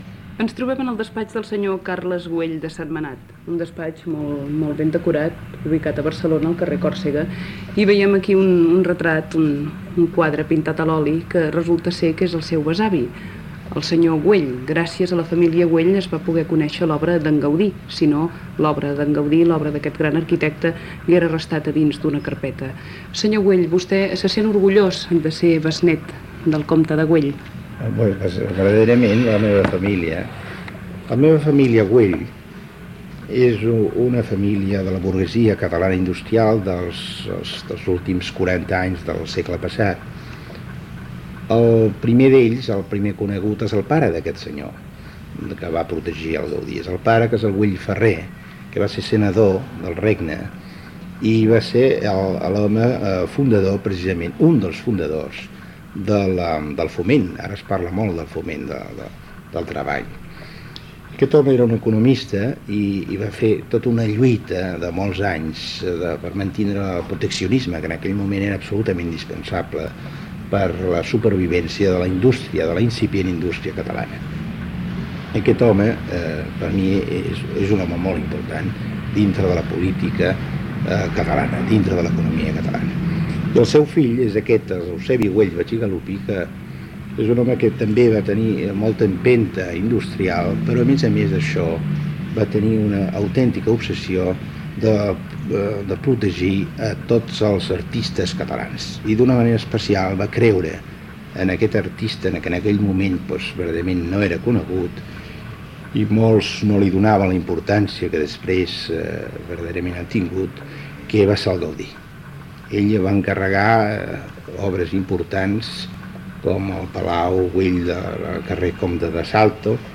Entrevista al diputat Carles Güell de Setmenat, feta al seu despatx de Barcelona
Informatiu